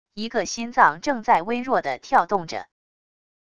一个心脏 正在微弱的跳动着wav音频